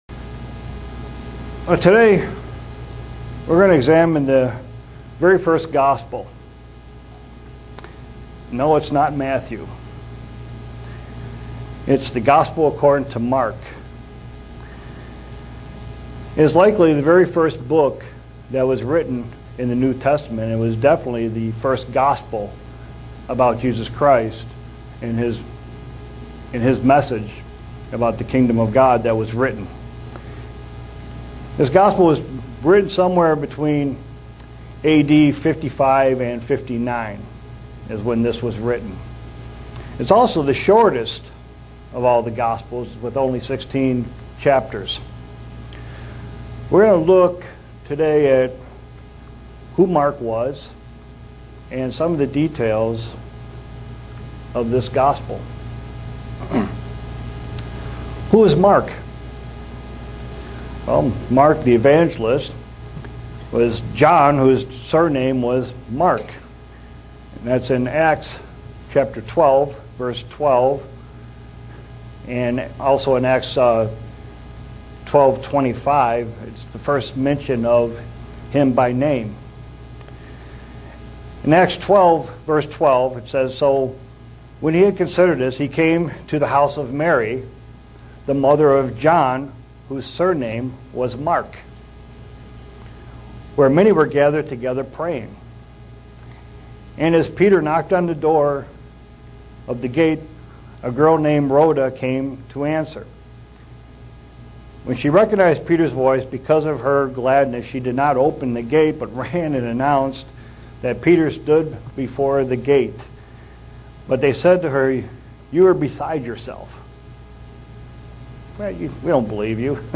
Given in Buffalo, NY
Print Examine who Mark was and how his Gospel details the life of Christ. sermons Studying the bible?